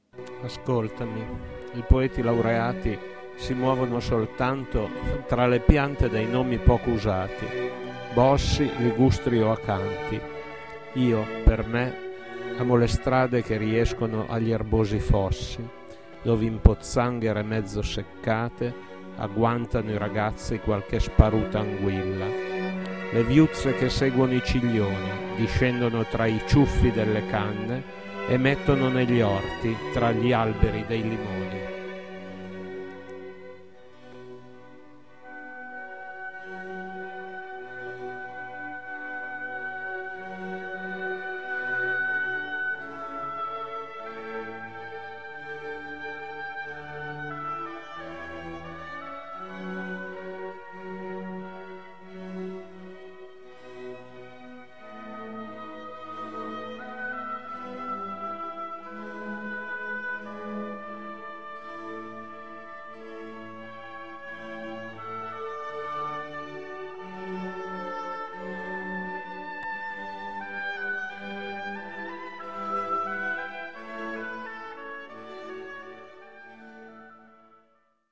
I limoni, lettura quarta strofa, analisi terza strofa